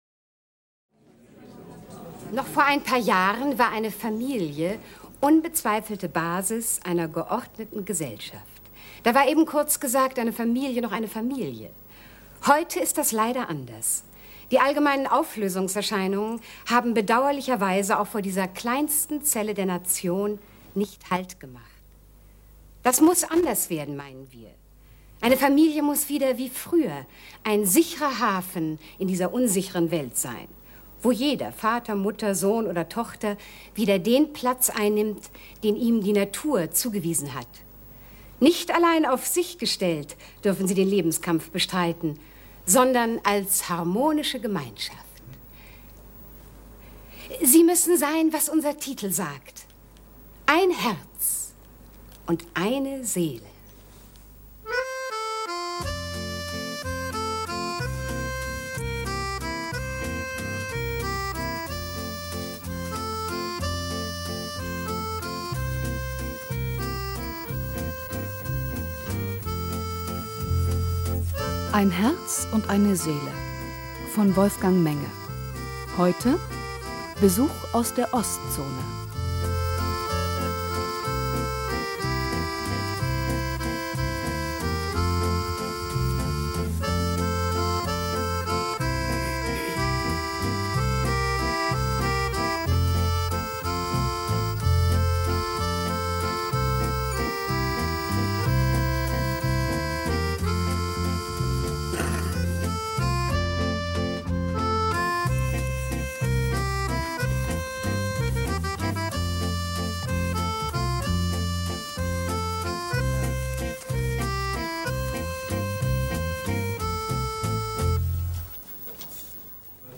Heinz Schubert, Elisabeth Wiedemann, Hildegard Krekel, Diether Krebs (Sprecher)
»Das geistige Auge ersetzt die Mattscheibe: Sitcom für den Kopf. « Westfälische Rundschau